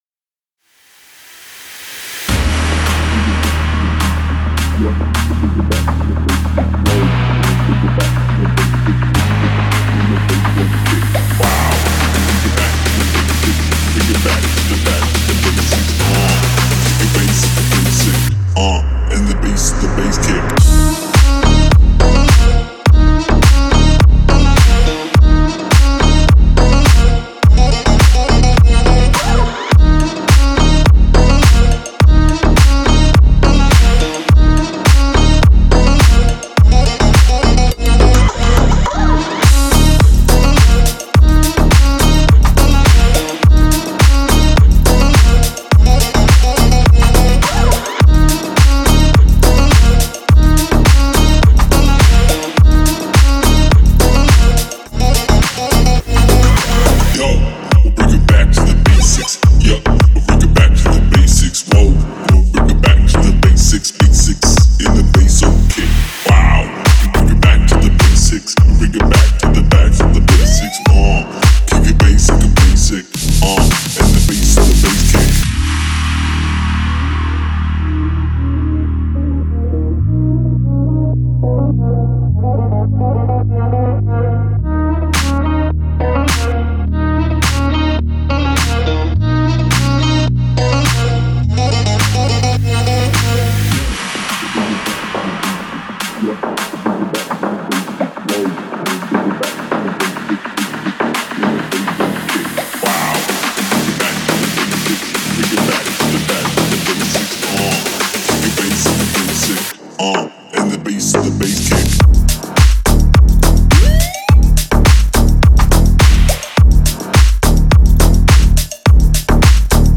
энергичная электронная композиция